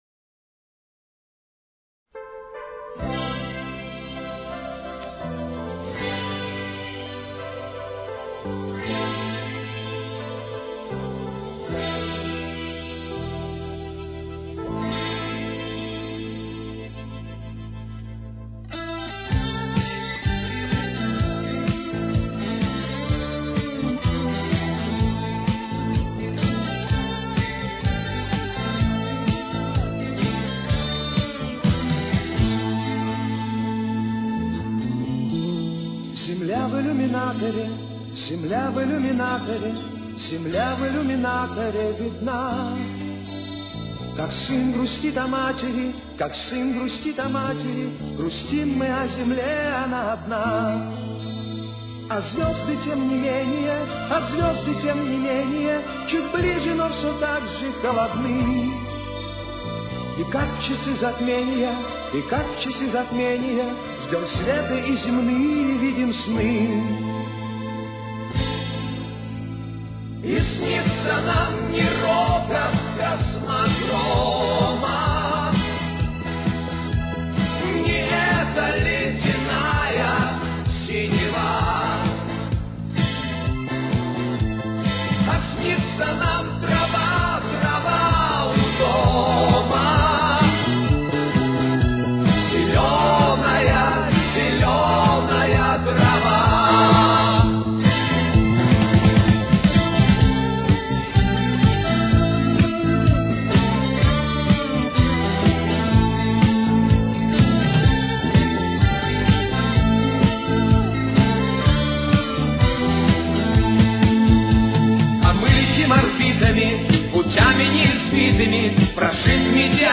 Тональность: Ля минор. Темп: 130.